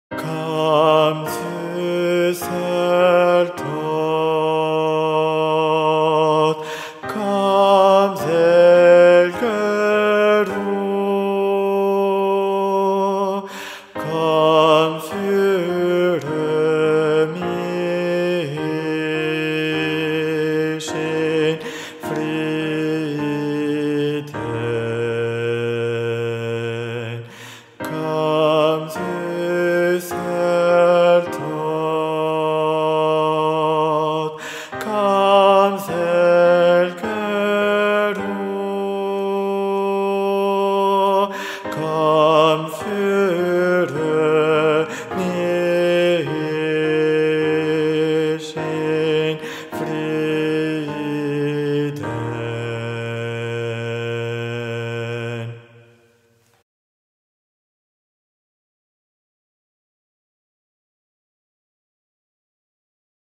Versions chantées
Guide Voix Altos Mp 3